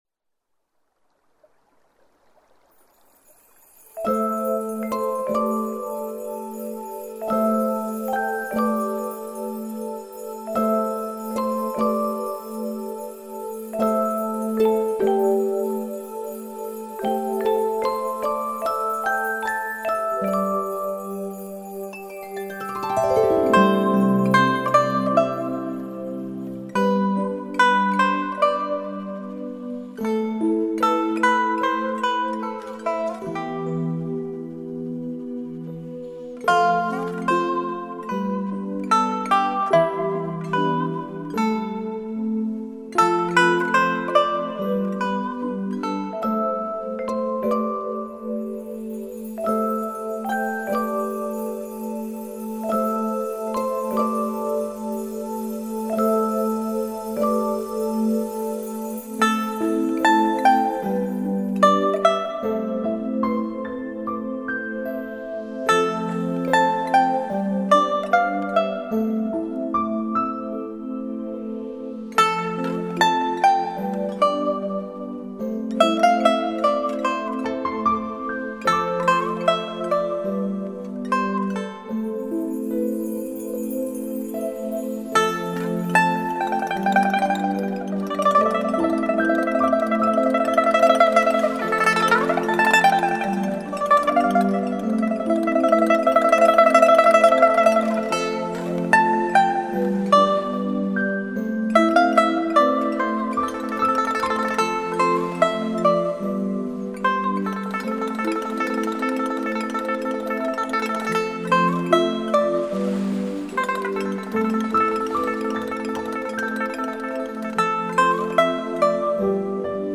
琴，而是另外一件西方的经典乐器——吉他。
琵琶与吉他，均为弹拨乐器，但却有着两种不同的文化背景。